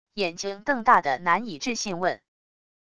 眼睛瞪大的难以置信问wav音频